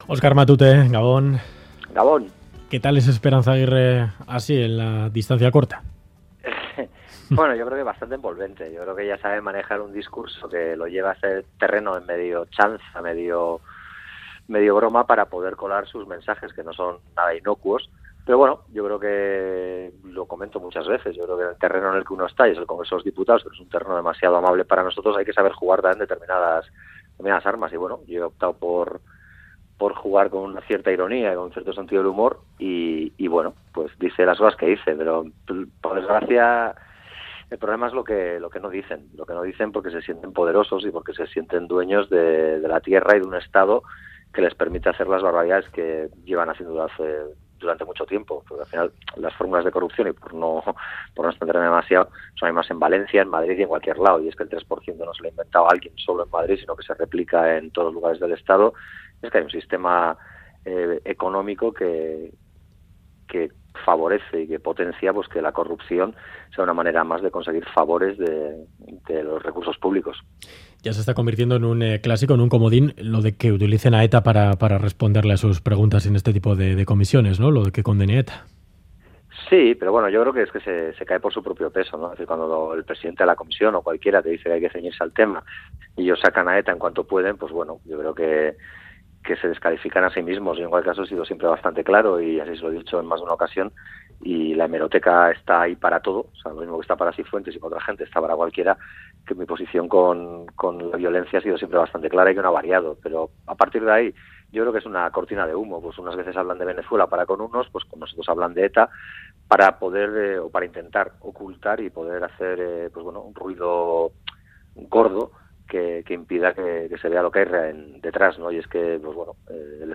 Audio: Entrevista en Ganbara de Radio Euskadi al diputado en el Congreso de los Diputados de EH Bildu Oskar Maute: 'A los vascos no nos conviene entablar pactos con gente que no cumple su palabra'